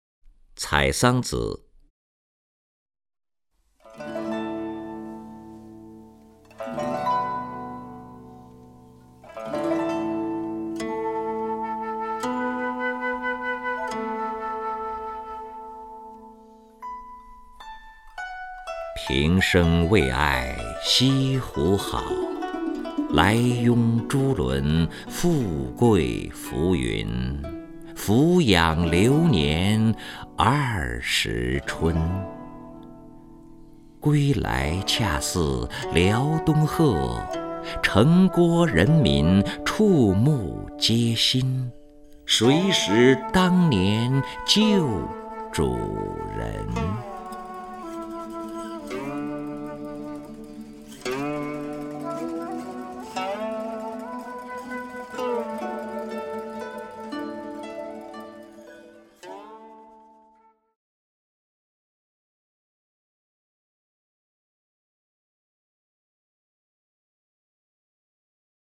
首页 视听 名家朗诵欣赏 任志宏
任志宏朗诵：《采桑子·平生为爱西湖好》(（北宋）欧阳修)
CaiSangZiPingShengWeiAiXiHuHao_OuYangXiu(RenZhiHong).mp3